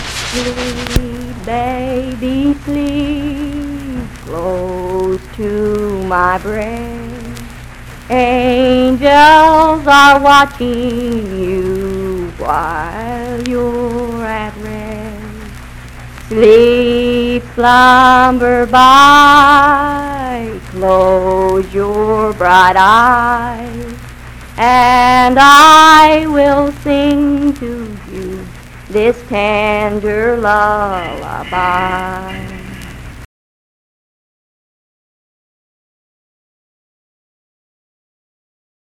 Unaccompanied vocal music
Verse-refrain 1(4).
Children's Songs
Voice (sung)
Wood County (W. Va.), Parkersburg (W. Va.)